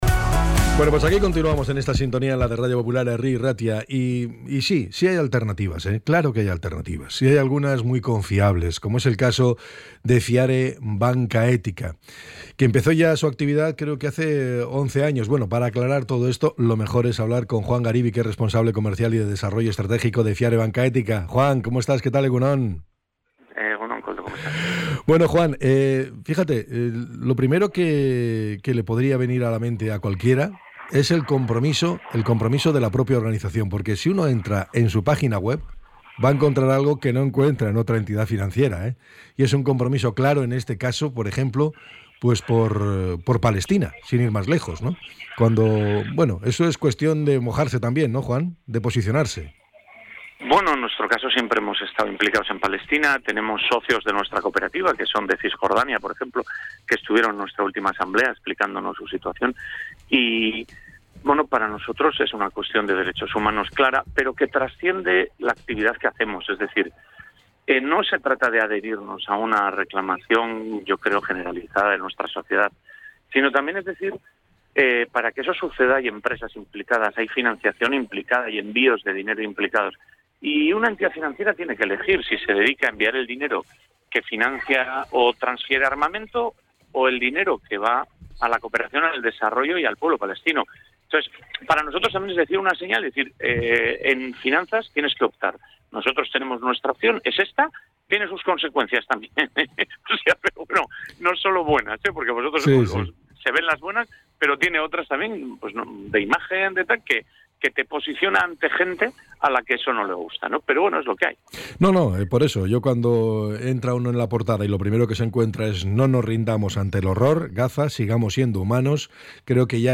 ENTREV.-FIARE-BANCA-ETICA-08-10.mp3